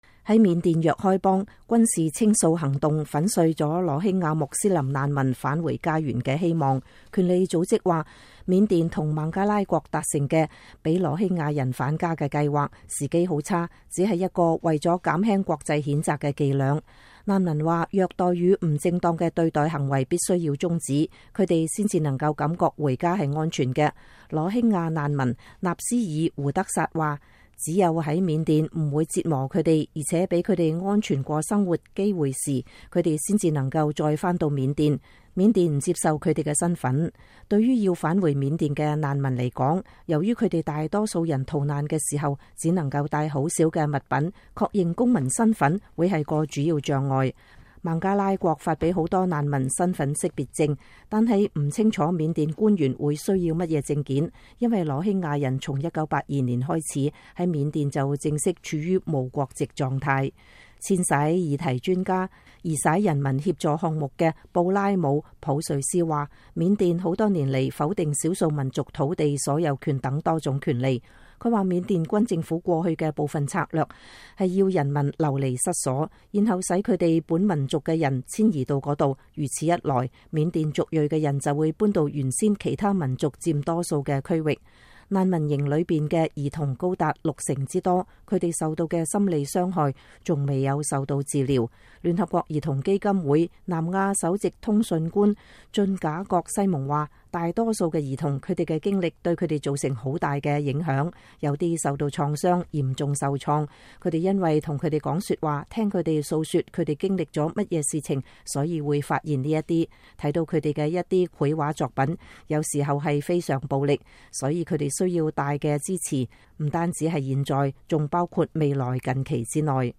美國之音記者訪問了人權與遷徙議題專家，討論六十多萬羅興亞難民將面對的未來。